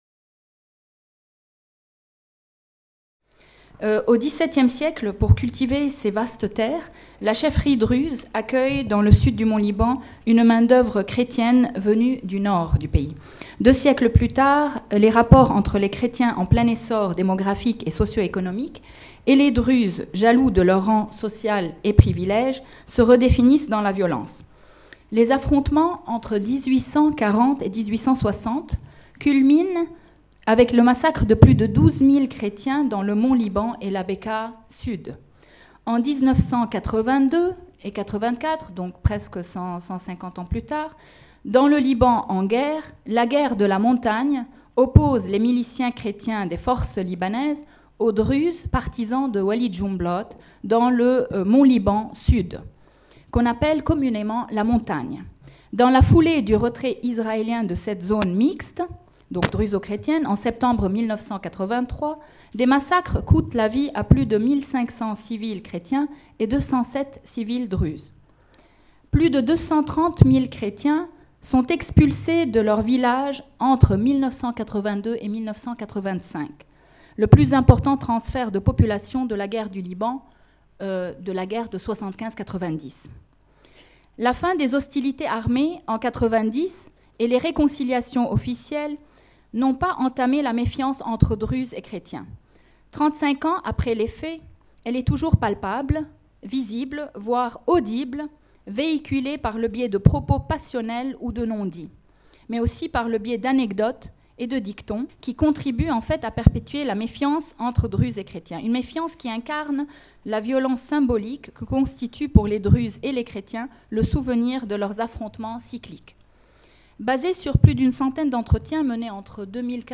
Cette conférence a été donnée dans le cadre du colloque Mémoires des massacres au XXe siècle organisé par le Centre de recherche en histoire quantitative (CRHQ) de l'Université de Caen et le Mémorial de Caen du 22 au 24 novembre 2017.